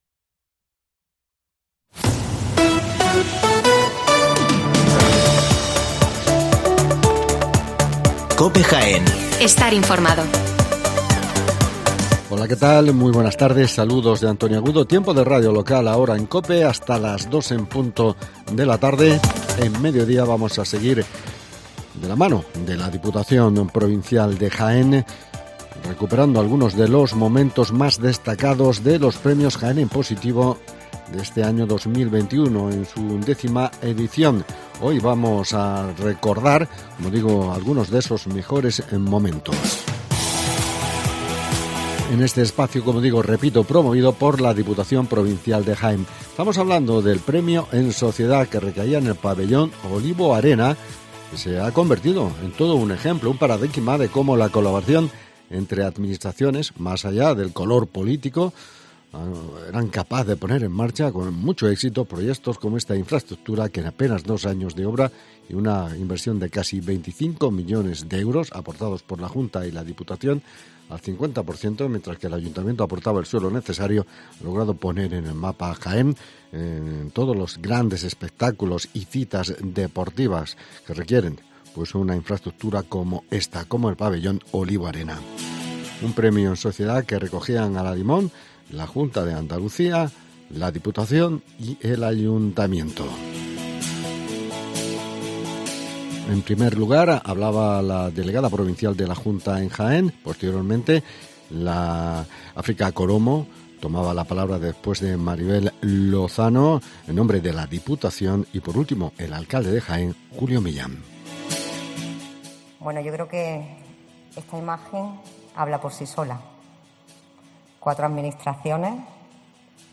Escuchamos las intervenciones de Maribel Lozano (Junta d eAndalucía), África Colomo (diputación) y Julio Millán (Ayuntamiento de Jaén) al recoger el premio Jaén en Positivo 2021